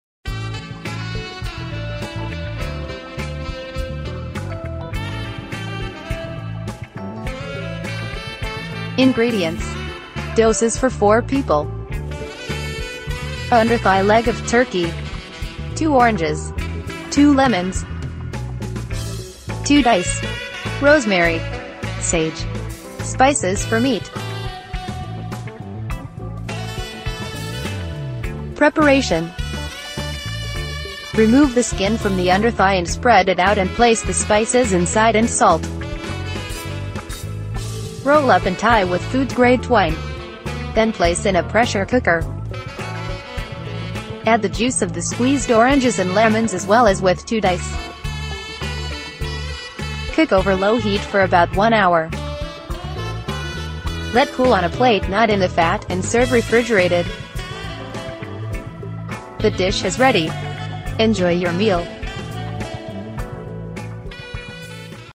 Each episode explores a unique roast recipe — from classic beef and pork roasts to innovative vegetarian and international twists. Hosted by passionate home cooks and professional chefs, the show dives into techniques, seasoning secrets, side pairings, and the stories behind each dish.